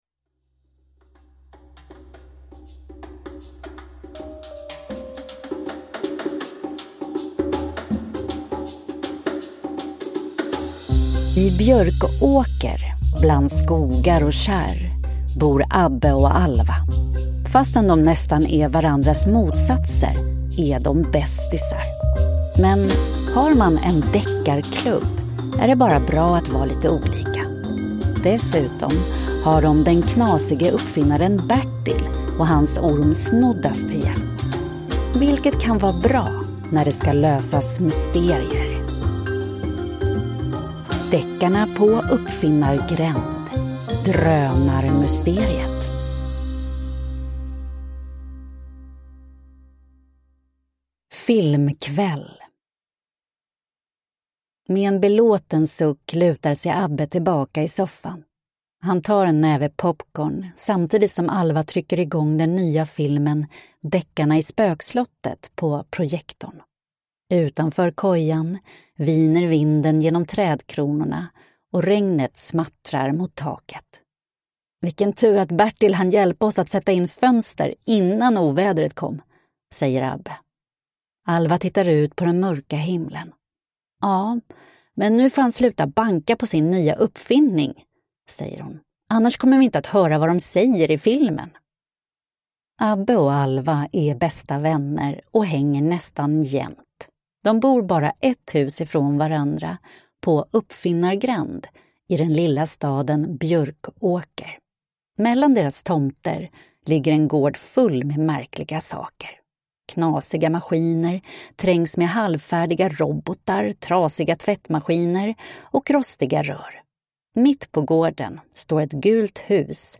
Drönarmysteriet – Ljudbok